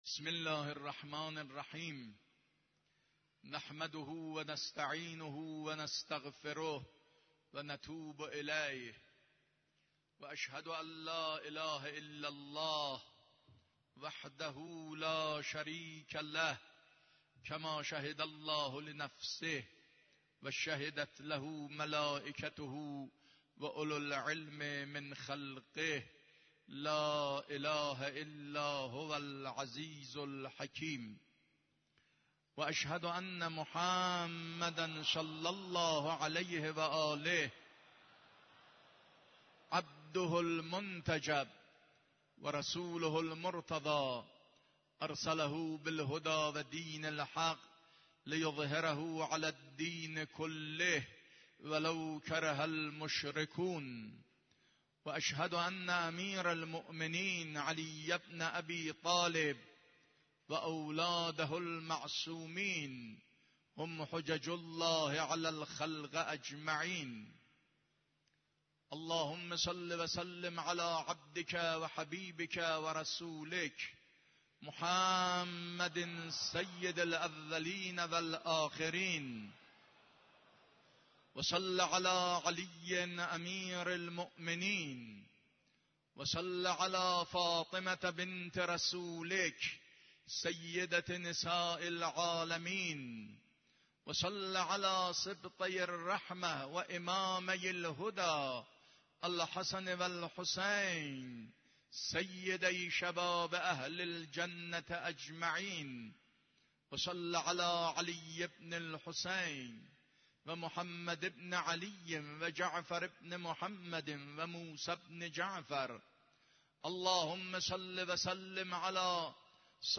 خطبه دوم نمازجمعه 6 تیرماه 93.mp3
خطبه-دوم-نمازجمعه-6-تیرماه-93.mp3